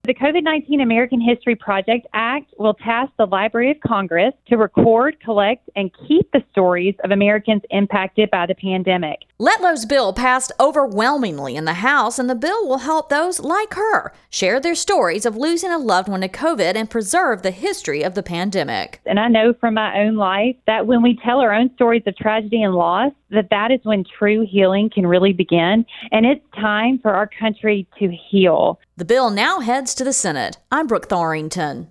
Congresswoman Julia Letlow (LA-5) presenting the COVID-19 American History Act to Congress.